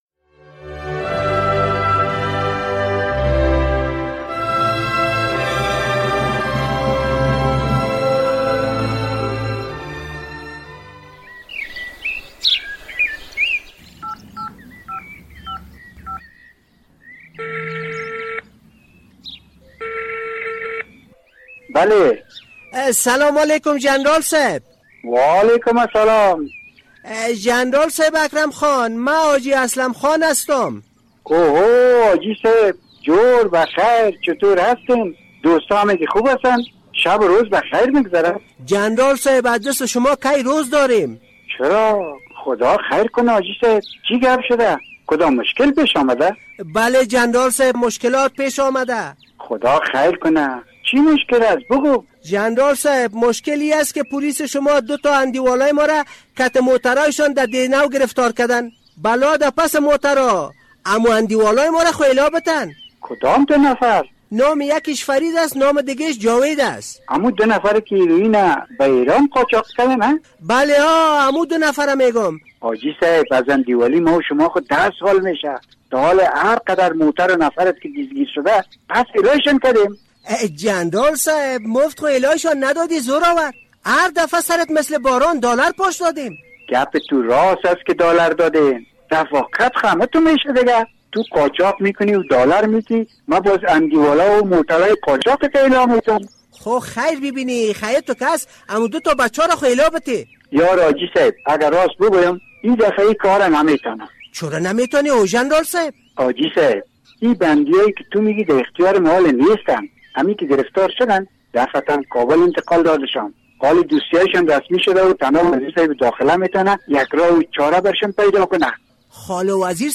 درامه: حاجی اسلم چگونه وزیر داخله را سبکدوش می‌کند؟